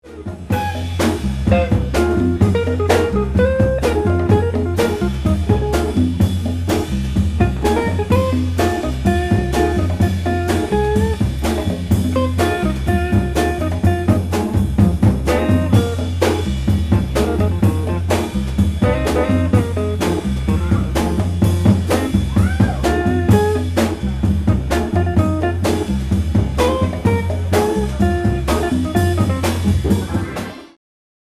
for a taste of R&B Soul Jazz.